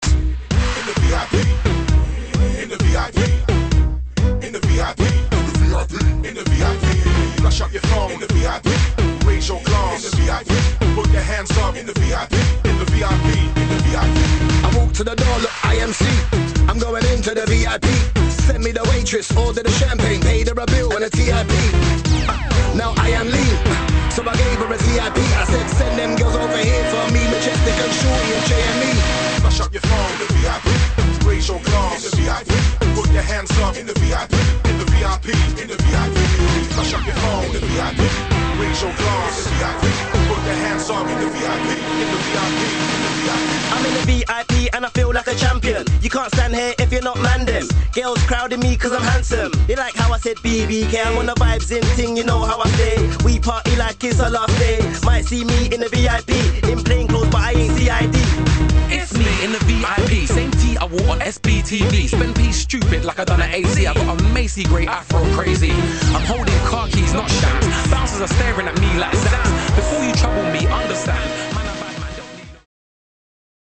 [ HOUSE | UKG | DUBSTEP ]